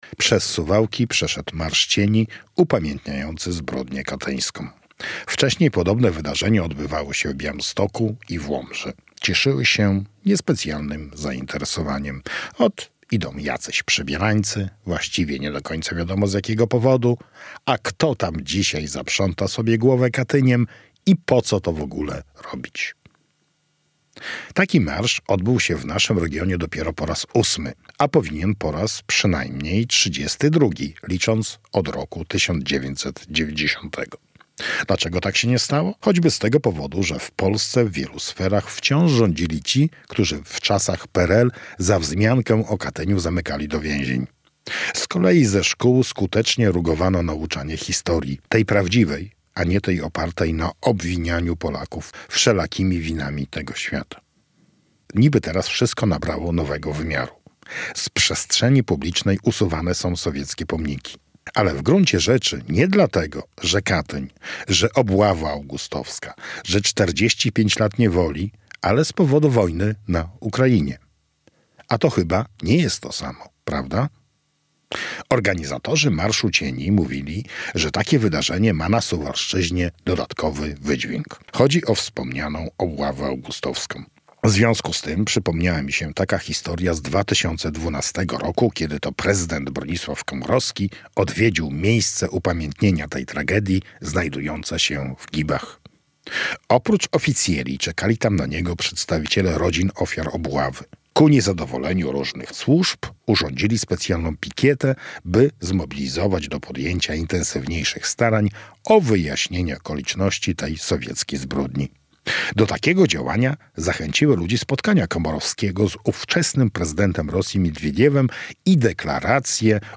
Radio Białystok | Felieton | To powinien być 32 marsz!